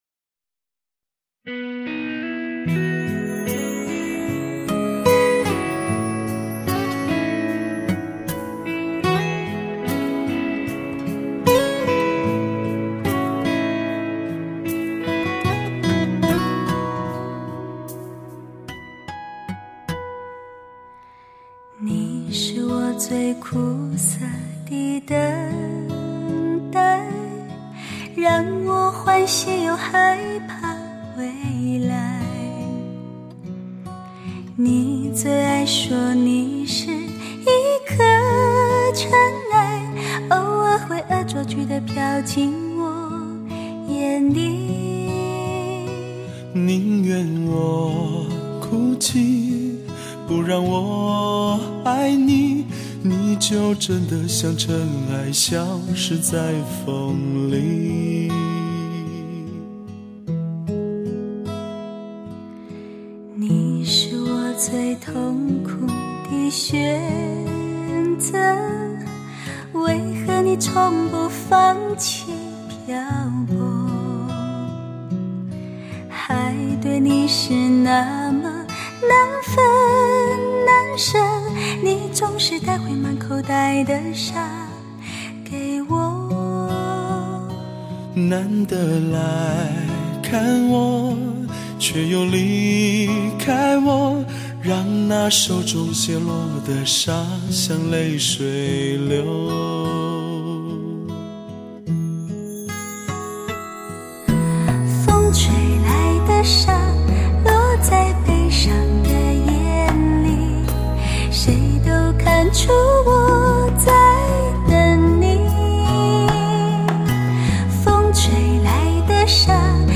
荡气回肠
编曲、录音、和声、配乐都极有水准